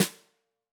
Index of /musicradar/Snares/Tama Wood